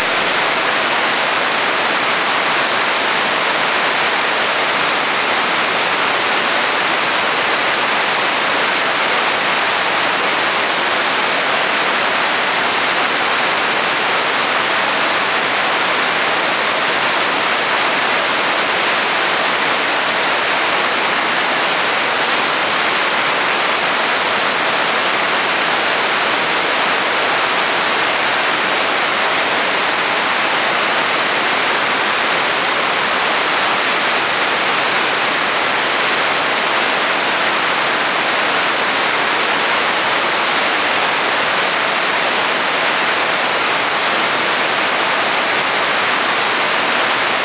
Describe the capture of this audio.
audio from the 114km QSO (1Mb 47 sec .WAV file). Path distance is 61.7km.